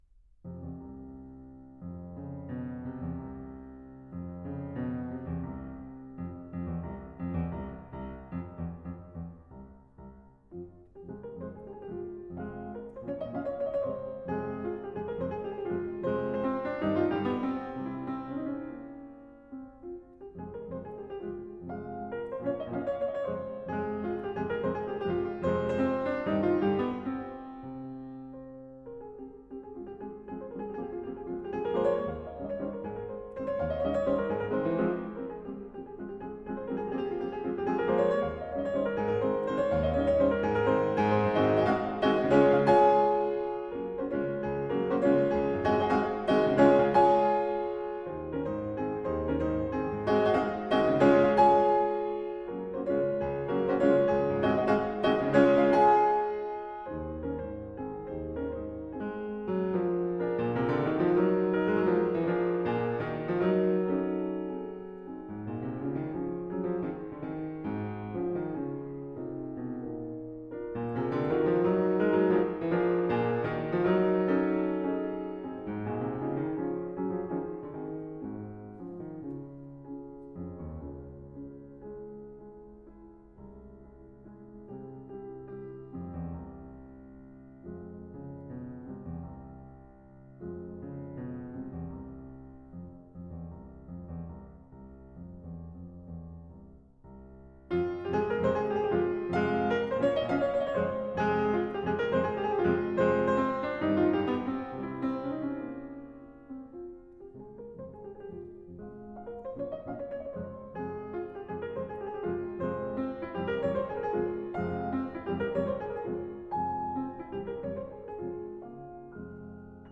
08.Mazurka in f-moll